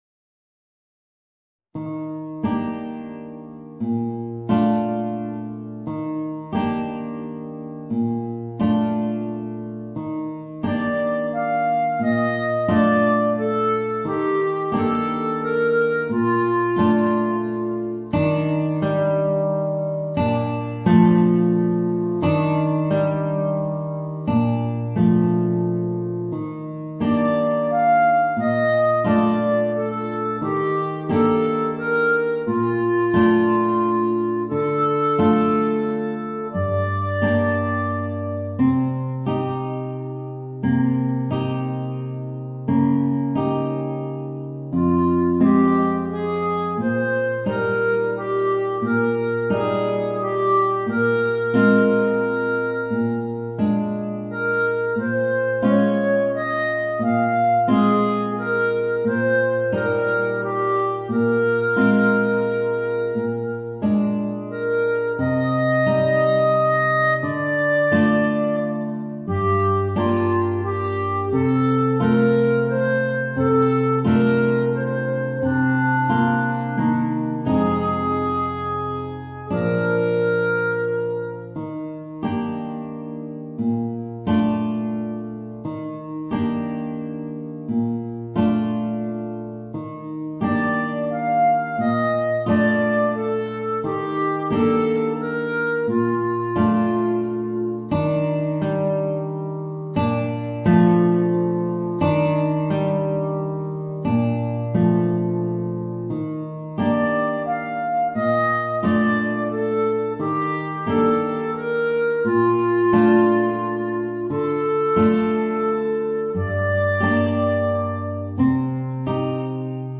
Voicing: Clarinet and Guitar